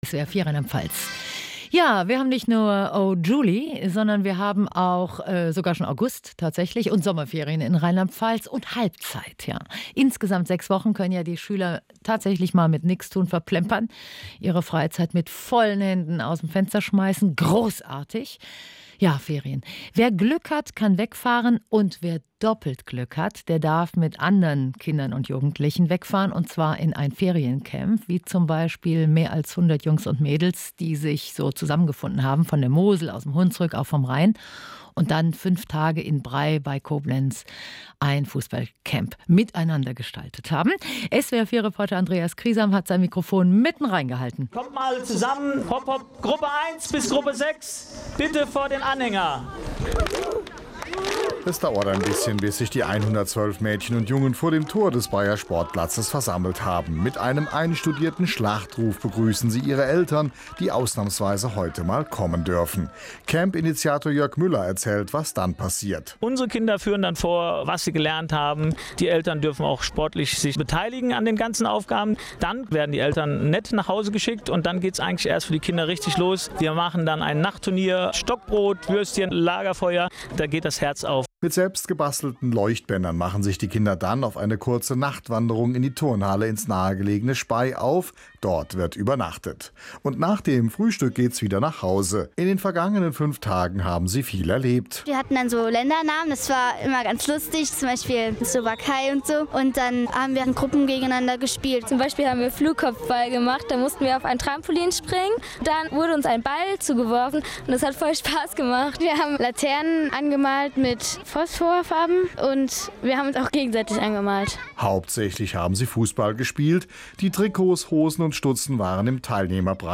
Radiobeitrag des SWR4 zum jobflyCamp 2016